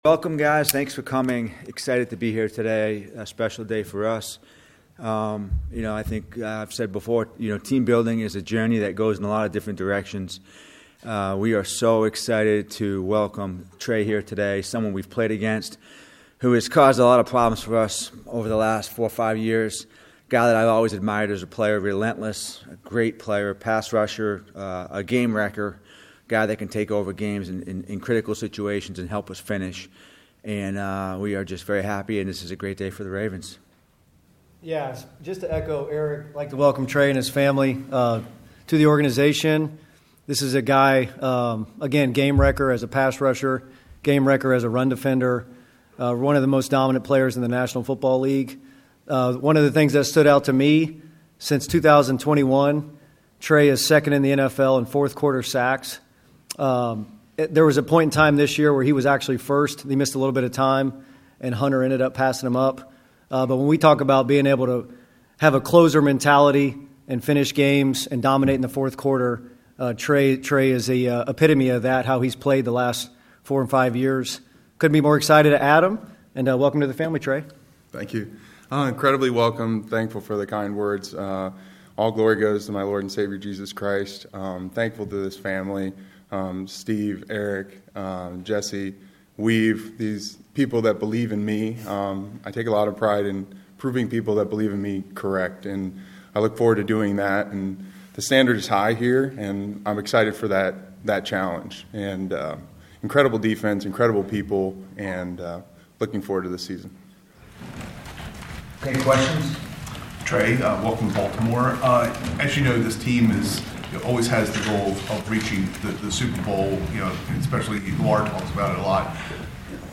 Trey Hendrickson meets with local media after officially signing with Ravens
Locker Room Sound